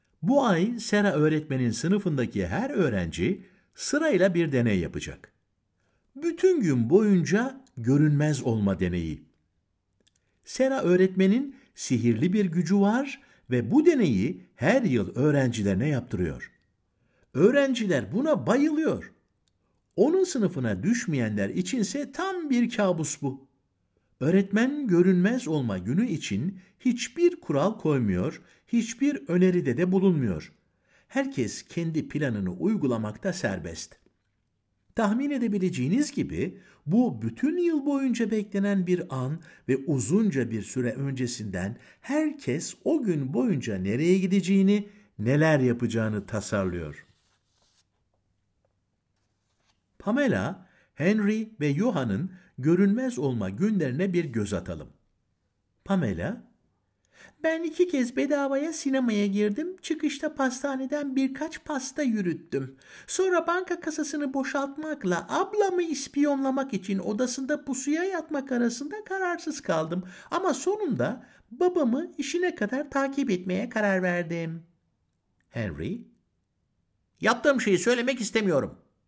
Seslendiren: Altan Erkekli